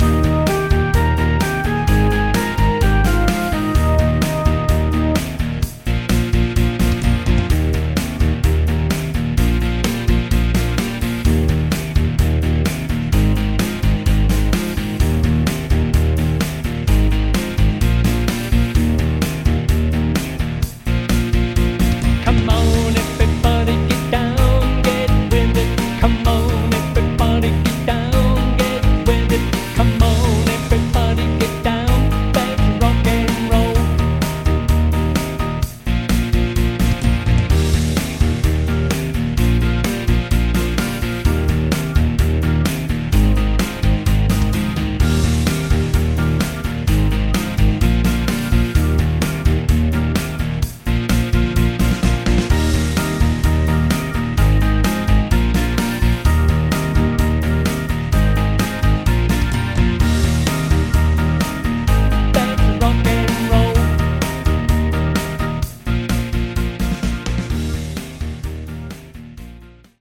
Harmony